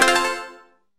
Turn Change SFX.mp3